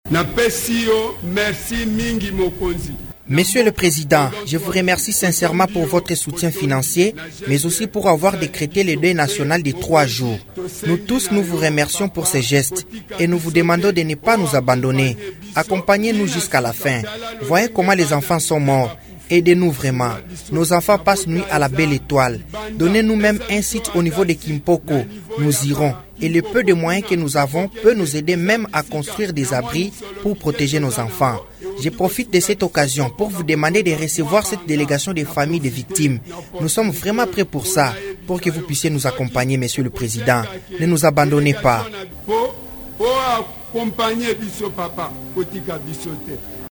Ce vœu a été exprimé ce mardi 20 décembre à Kinshasa, lors d’un culte œcuménique en faveur des sinistrés de la pluie diluvienne du mardi 13 décembre, organisé sur l’esplanade du palais du peuple.